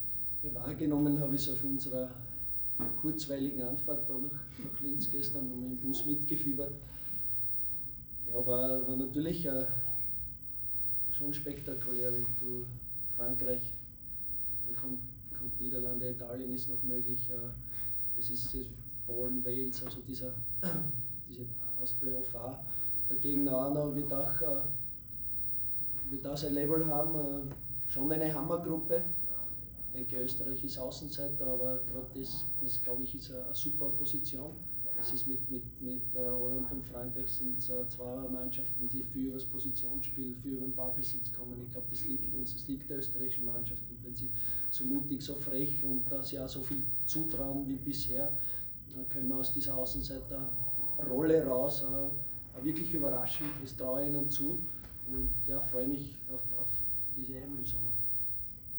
Cheftrainer Christian Ilzer bei der Pressekonferenz nach dem Unentschieden in Linz.